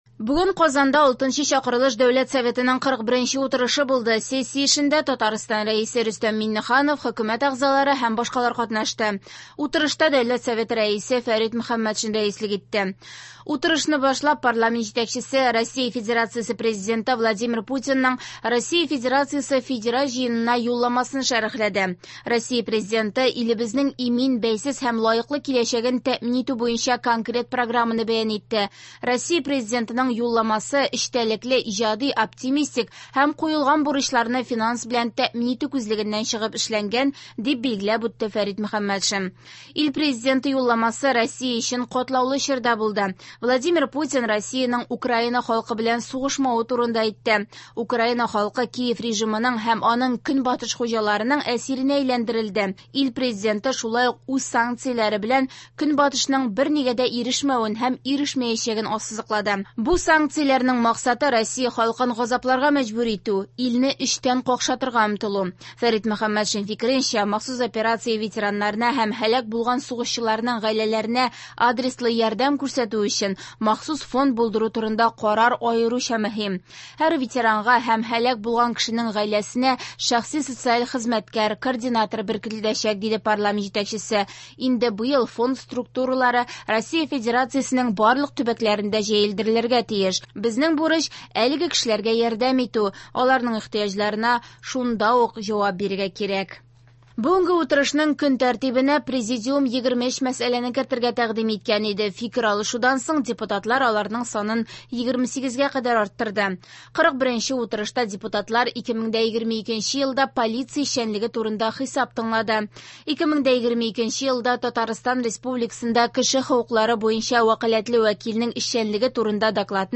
В эфире специальный информационный выпуск, посвященный 41 заседанию Государственного Совета Республики Татарстан 6-го созыва.